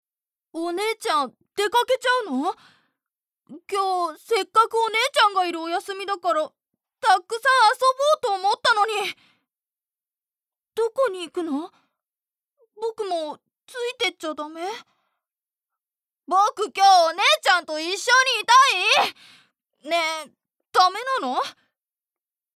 ボイスサンプル
小さい男の子